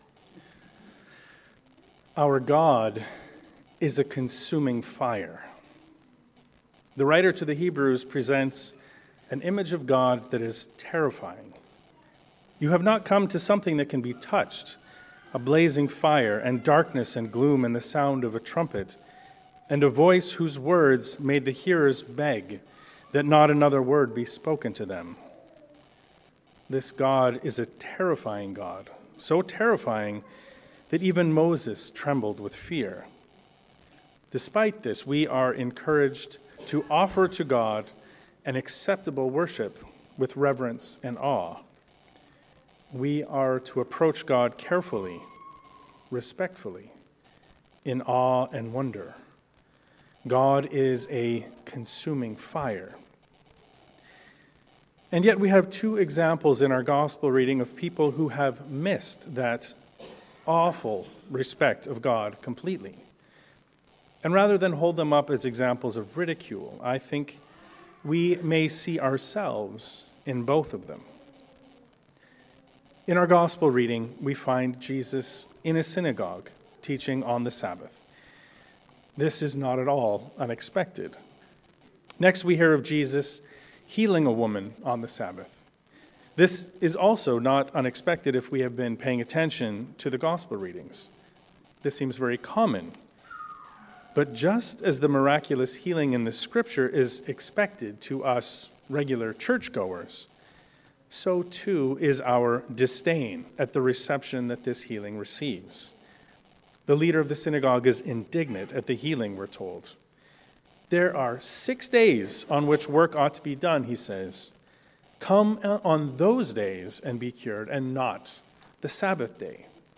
Myopic No More. A sermon on Luke 13